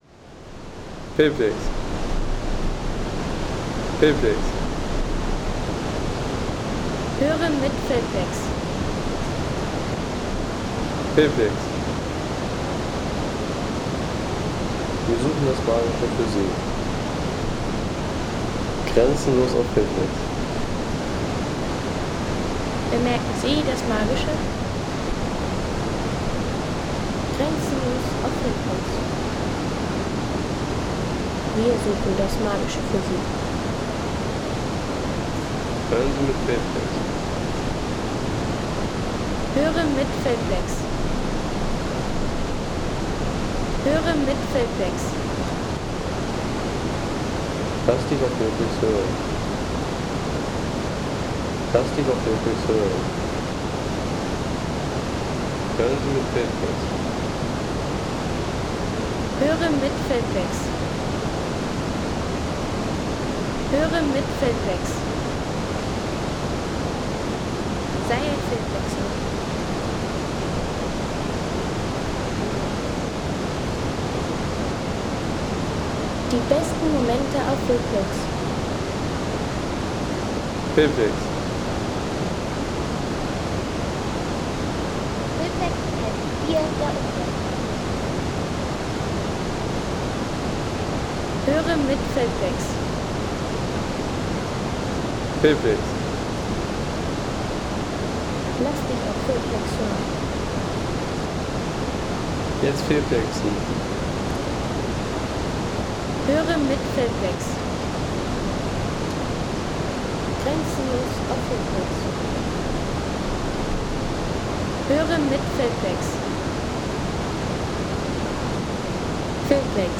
Landschaft - Wasserfälle